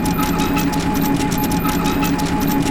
target.ogg